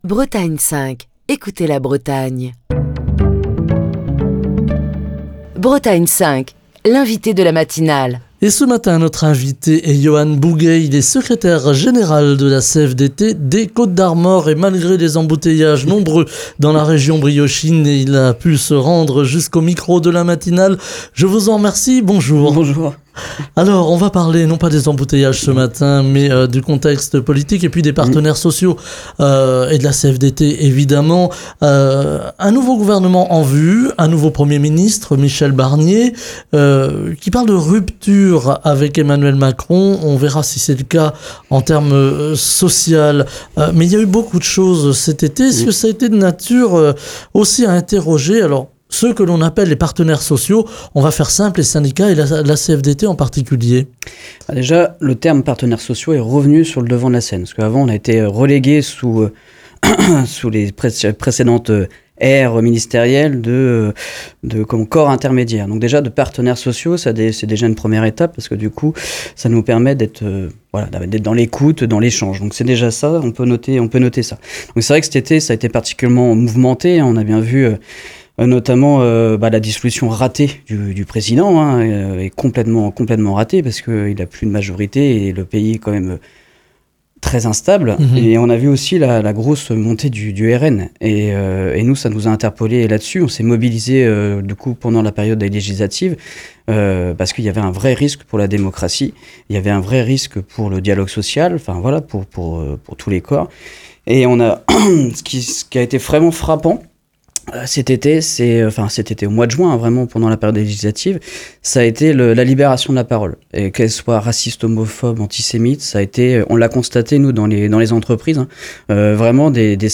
Émission du 18 septembre 2024.